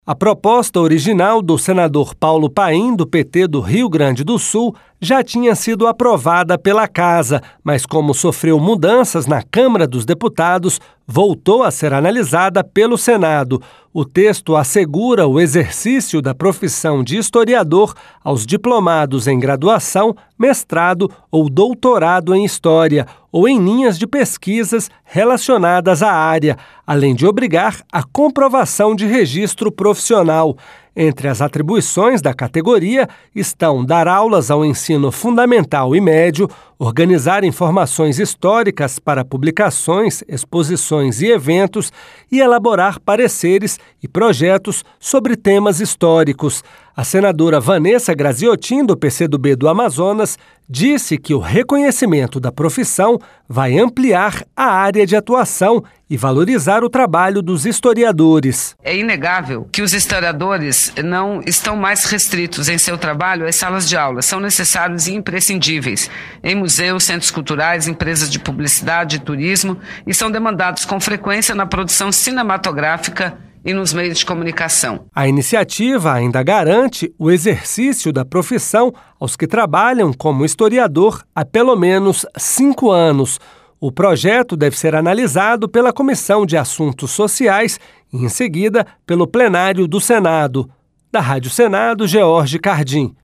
A senadora Vanessa Grazziotin, do PC do B do Amazonas, disse que o reconhecimento da profissão vai ampliar a área de atuação e valorizar o trabalho dos historiadores